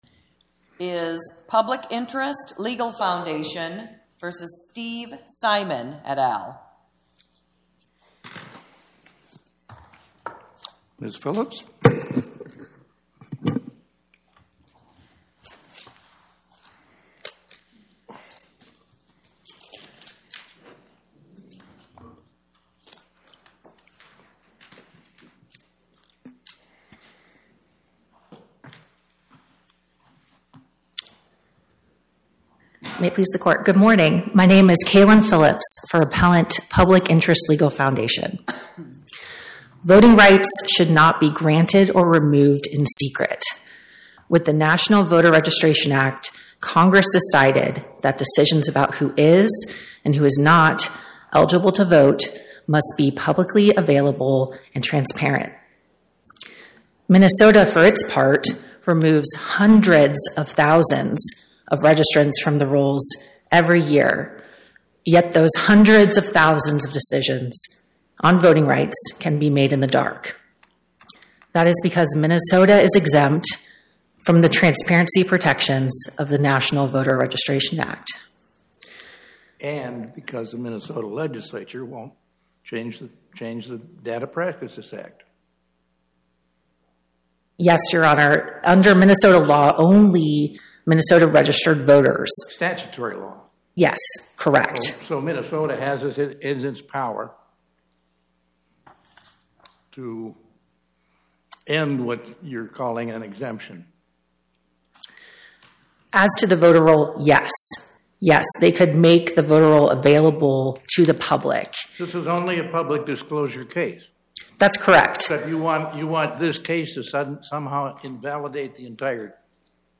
My Sentiment & Notes 25-1703: Public Interest Legal Foundation, Inc. vs Steve Simon Podcast: Oral Arguments from the Eighth Circuit U.S. Court of Appeals Published On: Tue Dec 16 2025 Description: Oral argument argued before the Eighth Circuit U.S. Court of Appeals on or about 12/16/2025